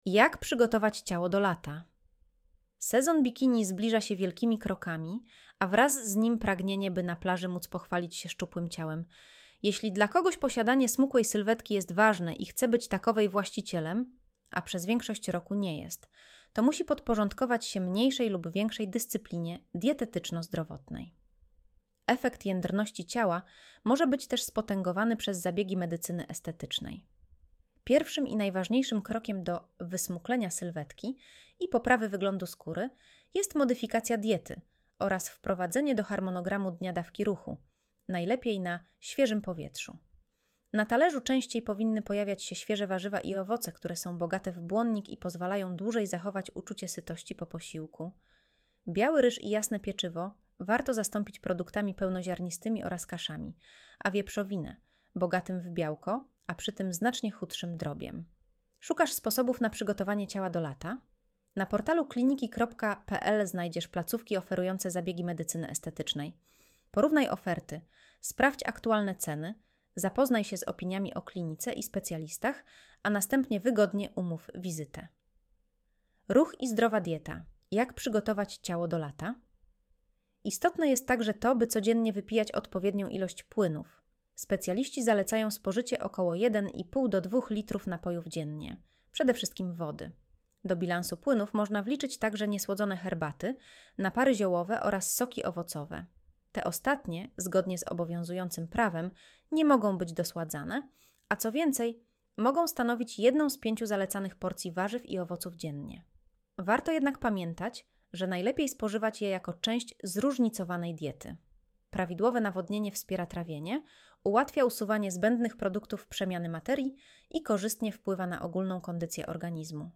Słuchaj artykułu Audio wygenerowane przez AI, może zawierać błędy